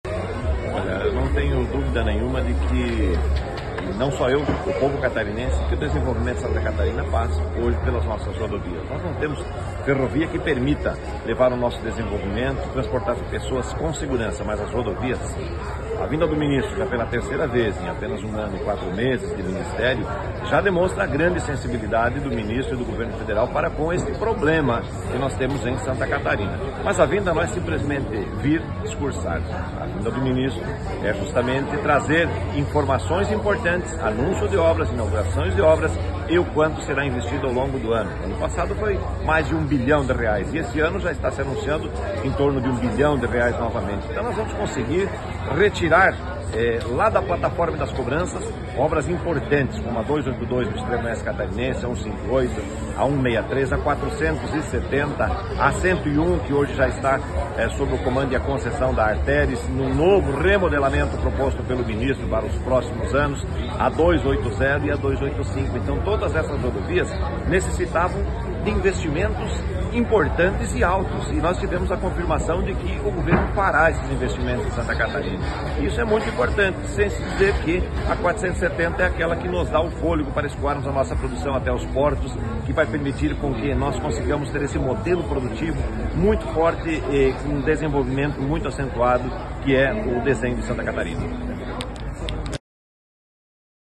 Fala_Mauro_De_Nadal.mp3